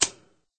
clunk_1.ogg